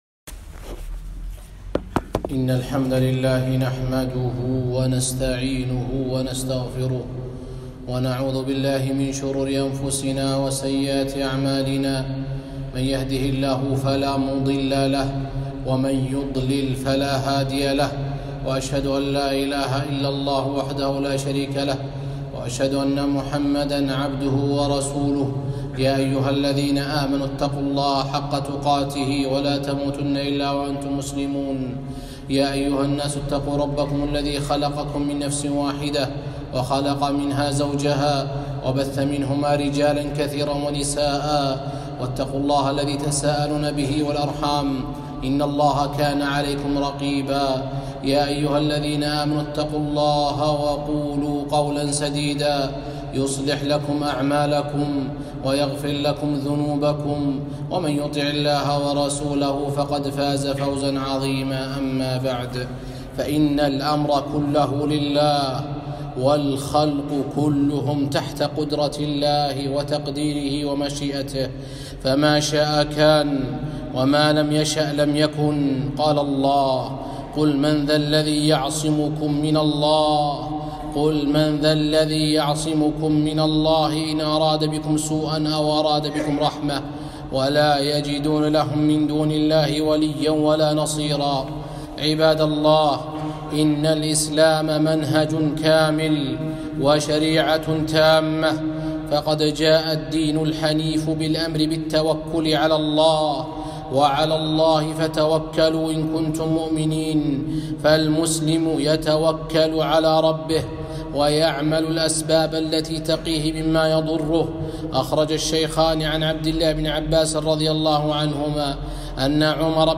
خطبة - وباء كورونا